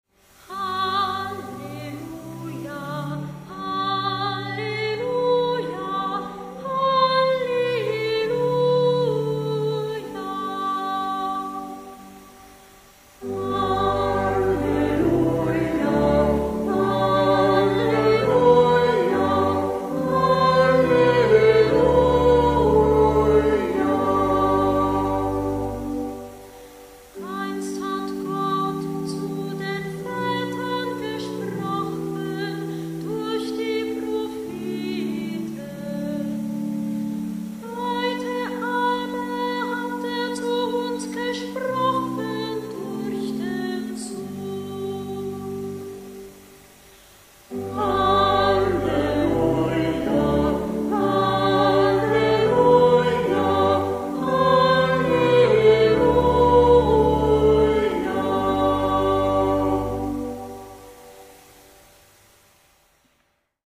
Ruf vor dem Evangelium 992 KB 1.
Orgel